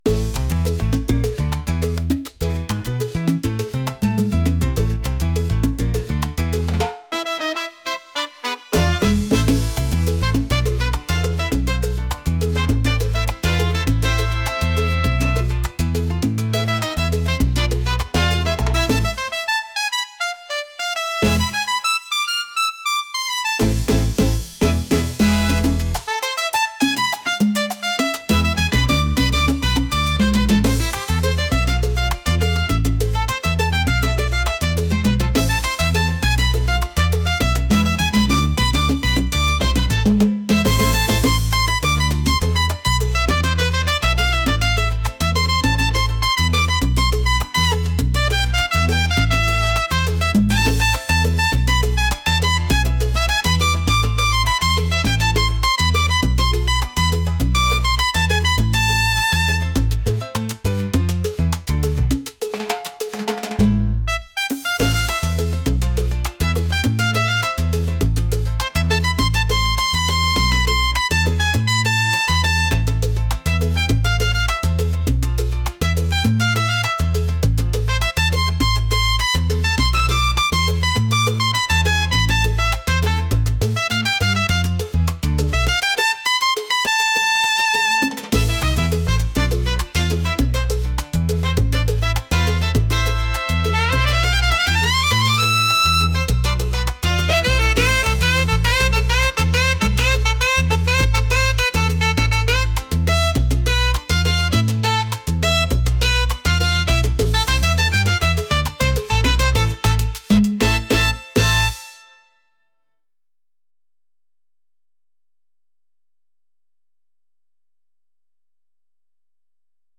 energetic | latin